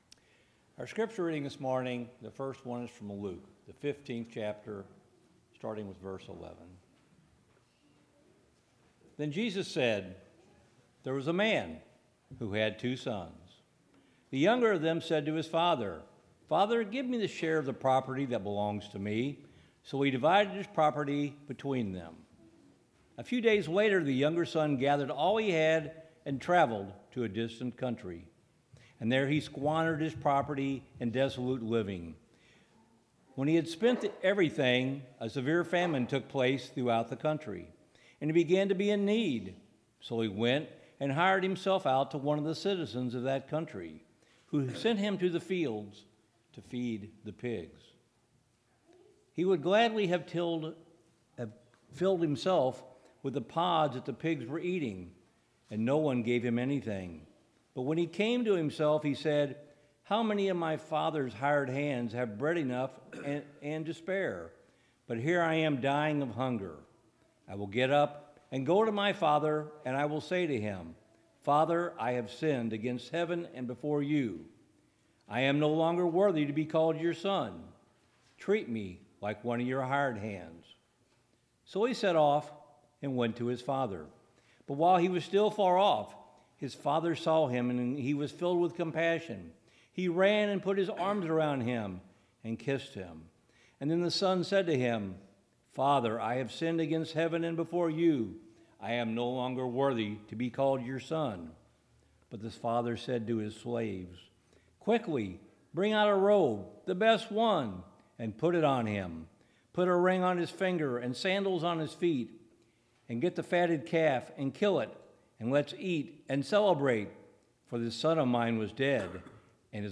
2 Samuel 11:2-11 Service Type: Sunday Morning Topics: Lust and Envy